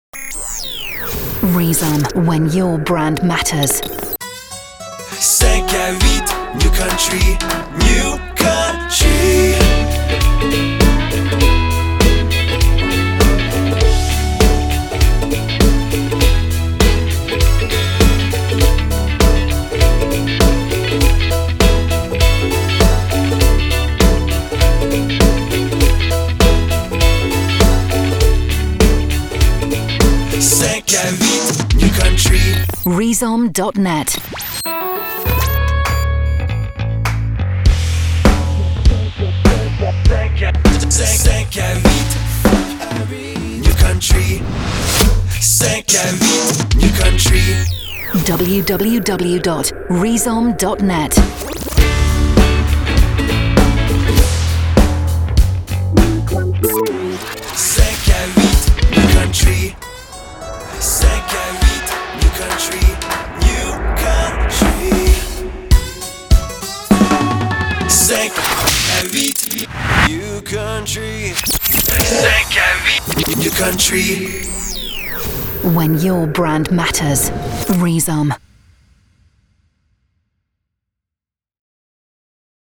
Country jingles - image sonore Quebec